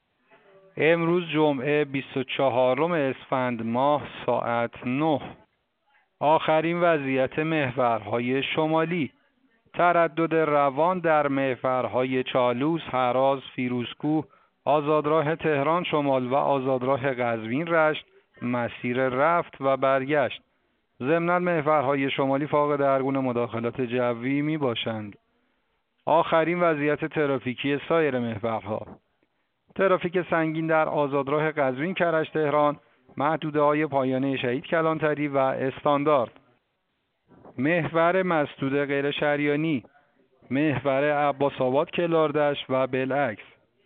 گزارش رادیو اینترنتی از آخرین وضعیت ترافیکی جاده‌ها ساعت ۹ بیست و چهارم اسفند؛